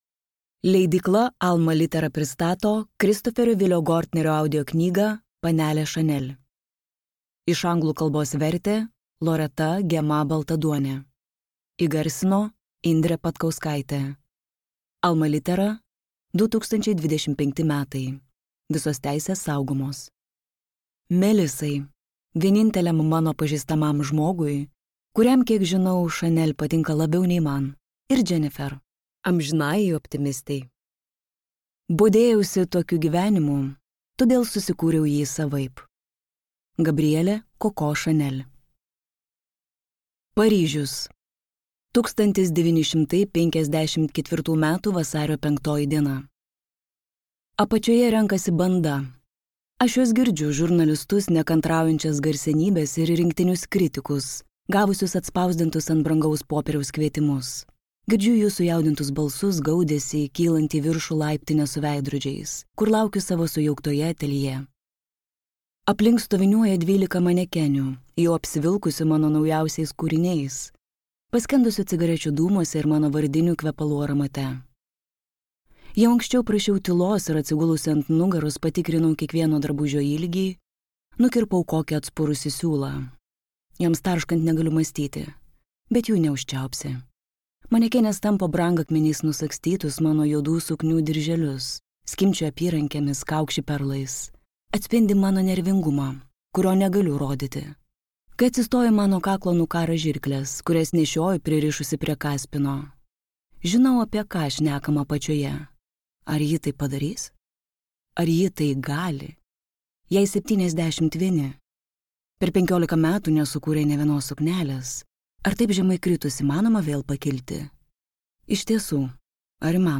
Panelė Chanel | Audioknygos | baltos lankos